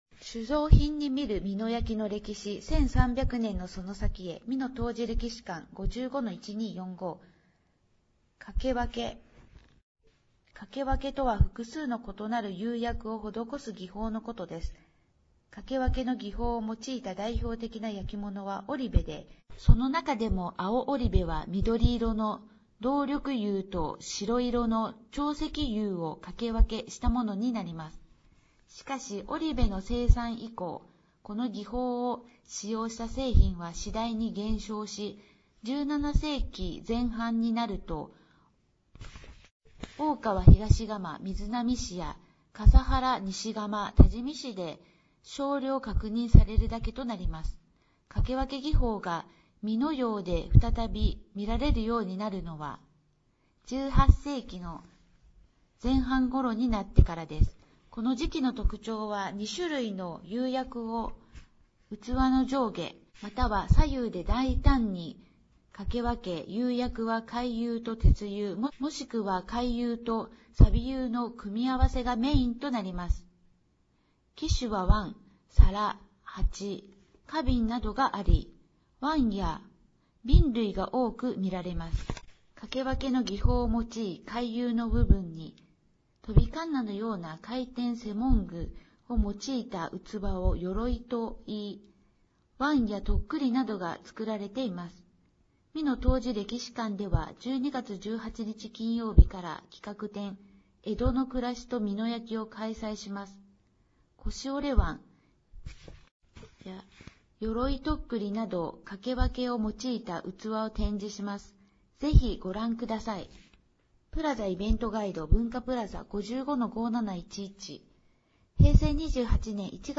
音声欄に表示があるものは、「声の広報」として、音声にてお聴きになれます。